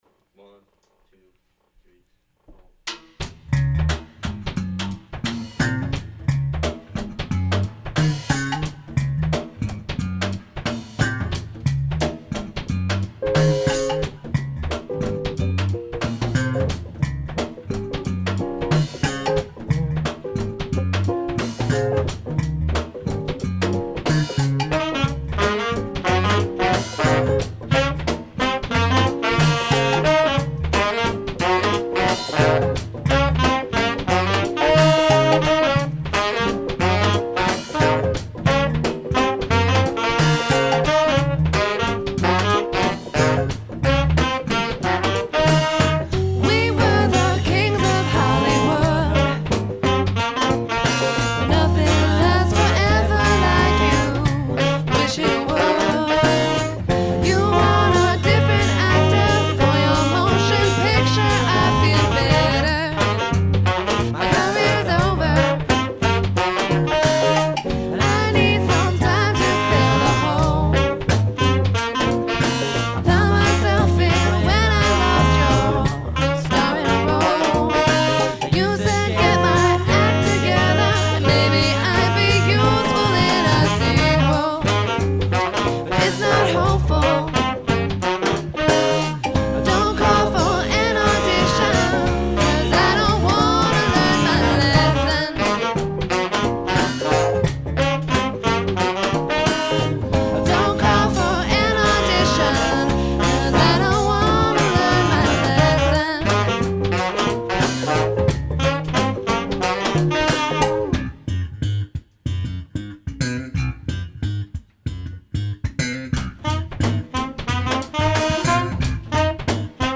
Recorded 10/30 and 11/1/06 at the band's practice space in Hadley, MA